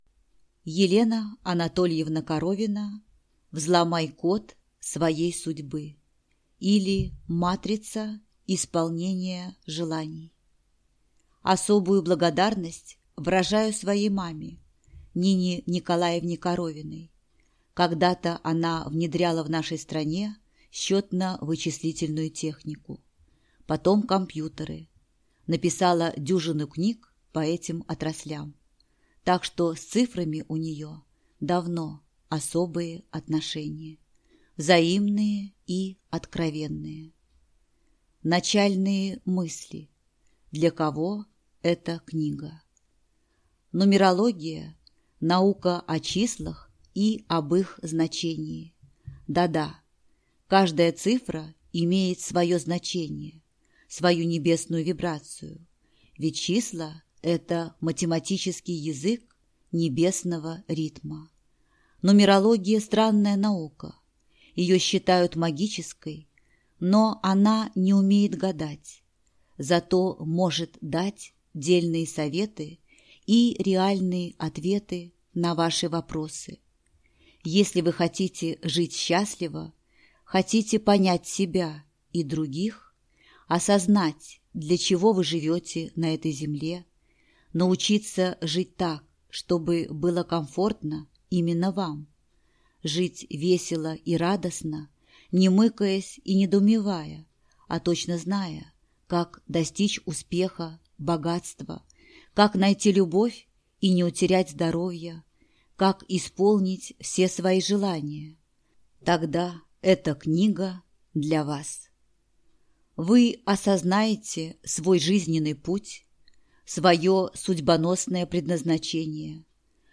Аудиокнига Взломай код своей судьбы, или Матрица исполнения желаний | Библиотека аудиокниг